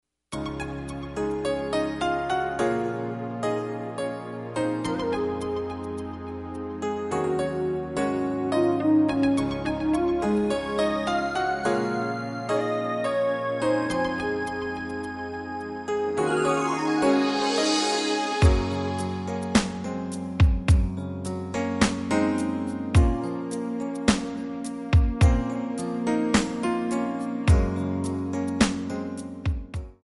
Backing track Karaoke
Pop, 1990s